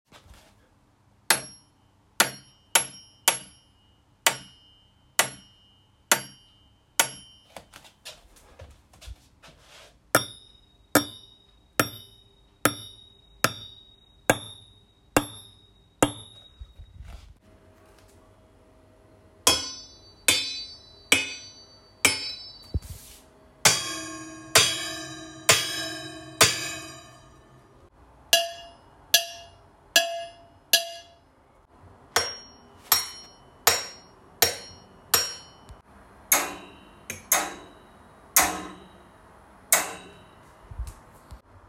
Олдскульная индастриальная перкуссия
День добрый) Вот песня старая, По ходу песни помимо ударов по живой установке используются разные лязгающие звуки.